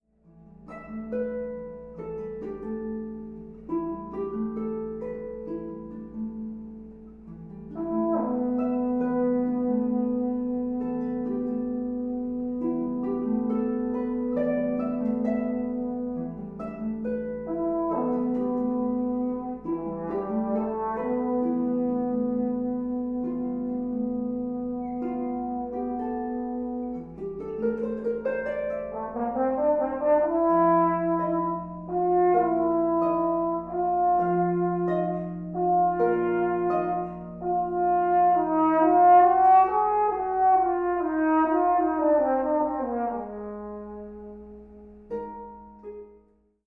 The result is a beautiful, atmospheric piece. It is tonal/modal, with hints of impressionism.
In Arkadia for Horn and Harp, Gary Schocker (b. 1959)
Both recordings are live and unedited, although I added a small amount of reverb to the files since we were not rehearsing in a concert hall.